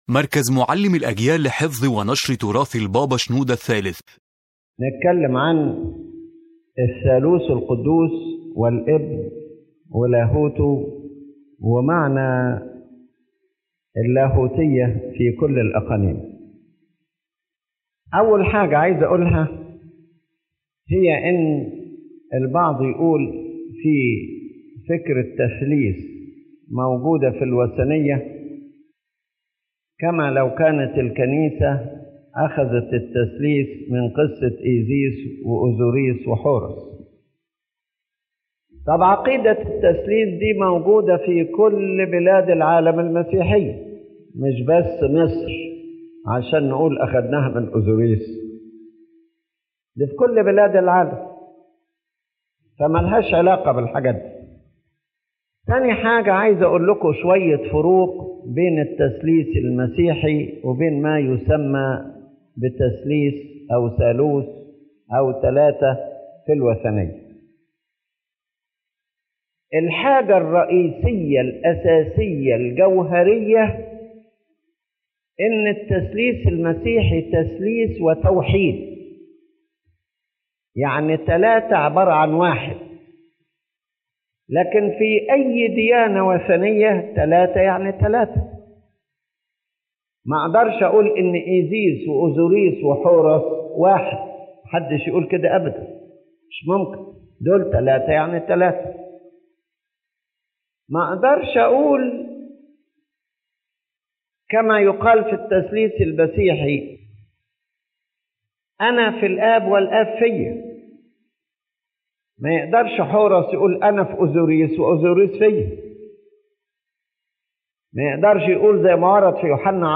The General Message of the Lecture